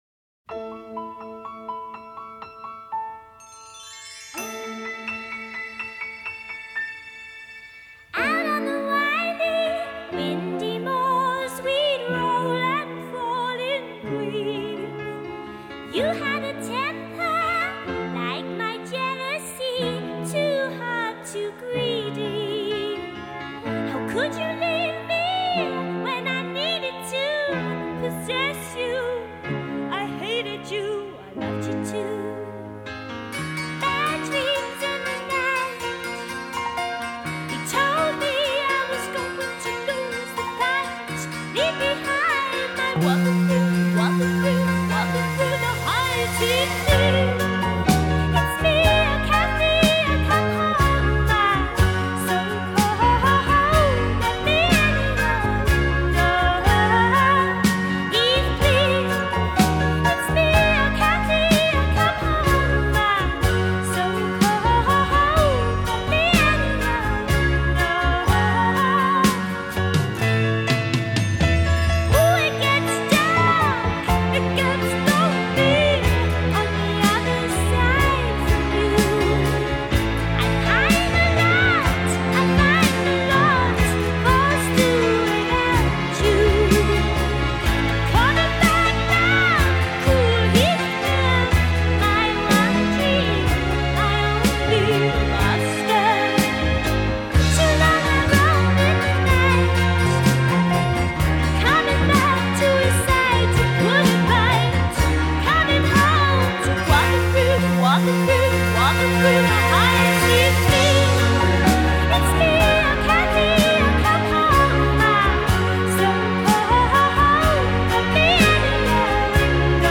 It’s both grand and unsettling….
lush orchestration
guitar solo